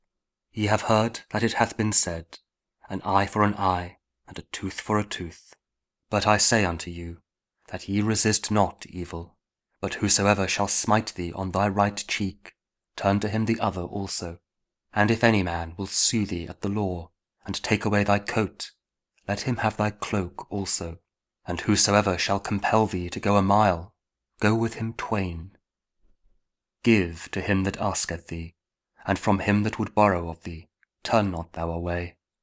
Matthew 5: 38-42 – Week 11 Ordinary Time, Monday (King James Audio Bible, Spoken Word)